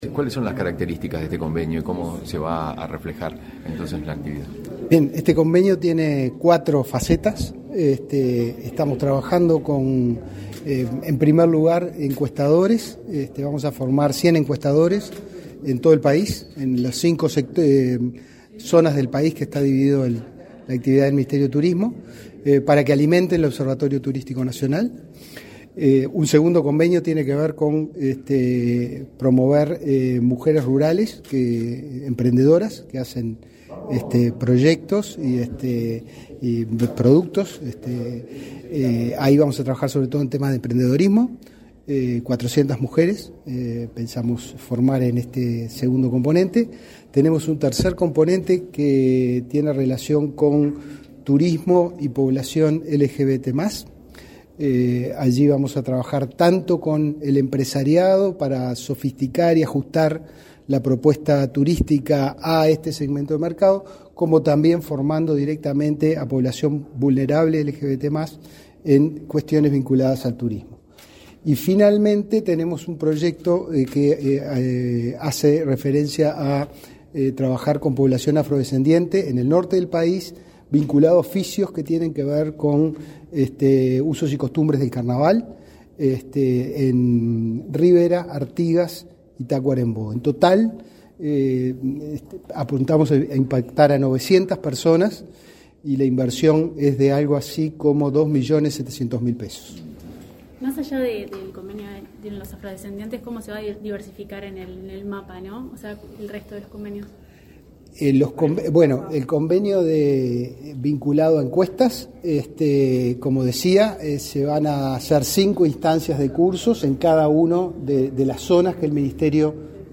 Declaraciones a la prensa del director general de Inefop, Pablo Darscht
El Instituto Nacional de Formación Profesional (Inefop) participó, junto a los ministerios de Trabajo y Seguridad Social y de Turismo, este 16 de marzo, en la firma de convenios de capacitación dirigidos a la población LGTB+, encuestadores de turismo, mujeres emprendedoras de turismo rural y población afrodescendiente. Tras el evento, Darscht efectuó declaraciones a la prensa.